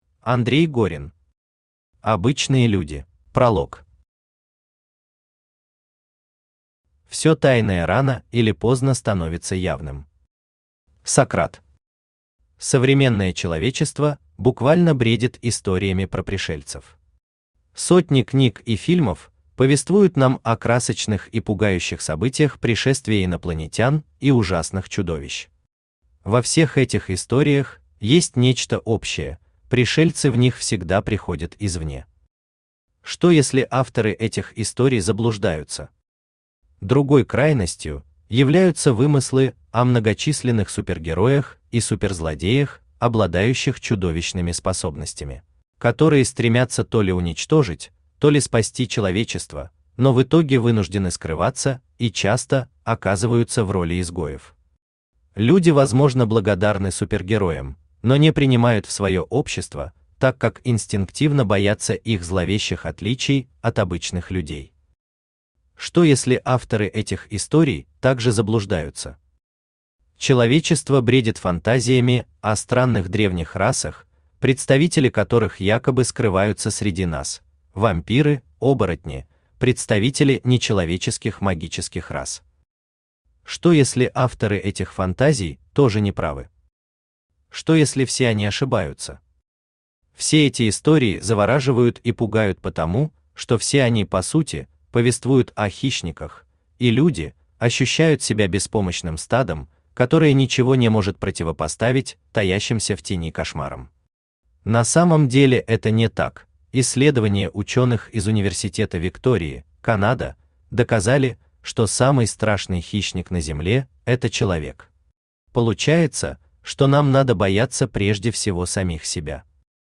Аудиокнига Обычные люди | Библиотека аудиокниг
Aудиокнига Обычные люди Автор Андрей Горин Читает аудиокнигу Авточтец ЛитРес.